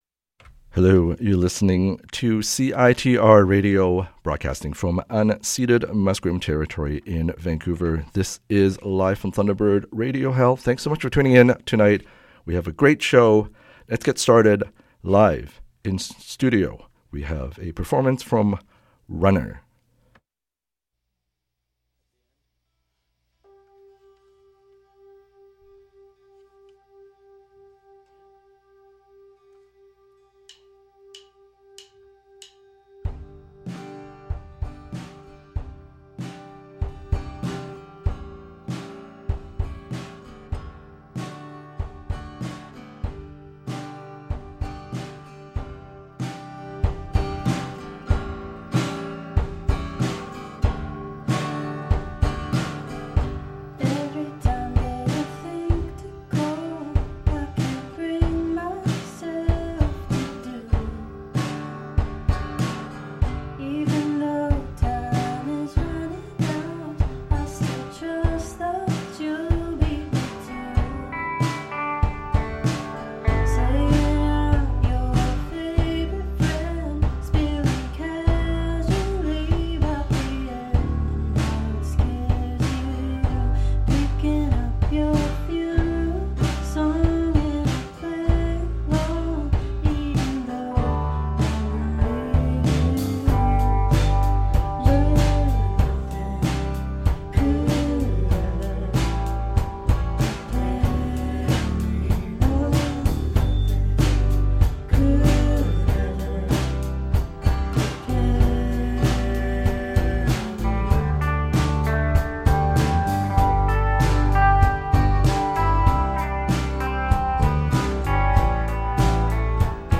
Live in studio performance